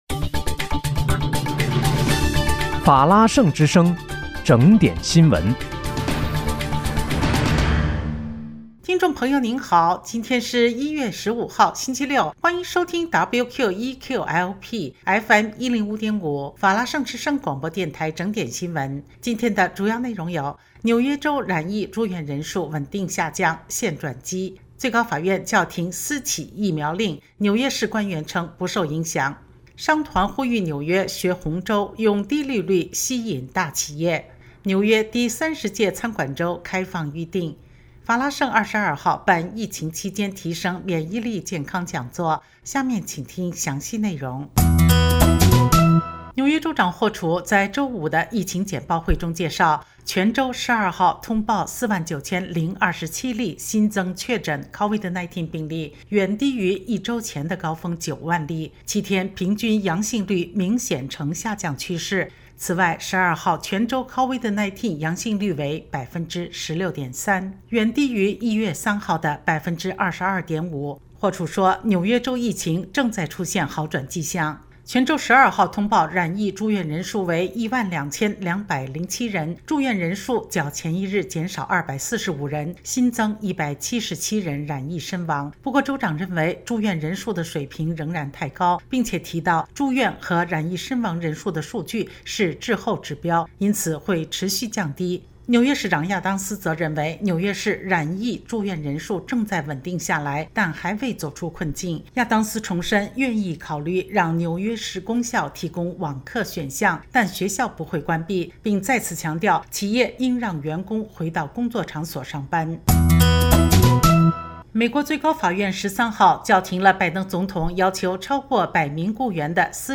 1月15日（星期六）纽约整点新闻